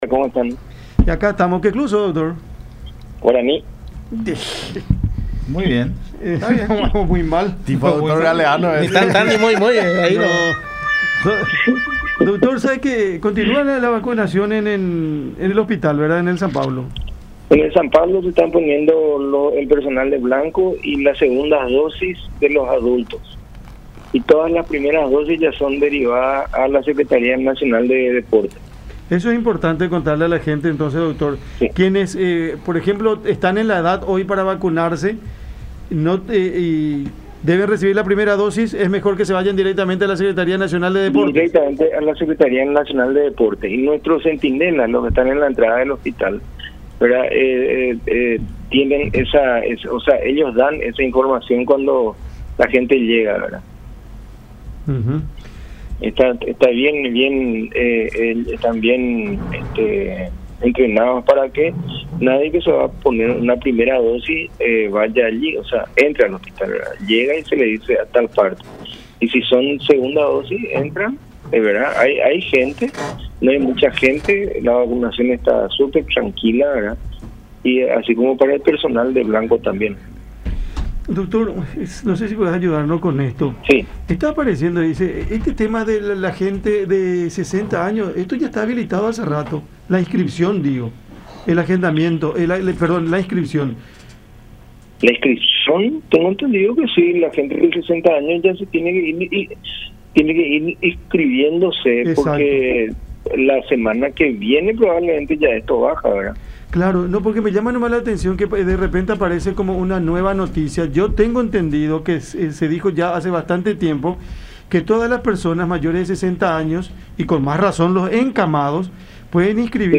en conversación con Cada Mañana por La Unión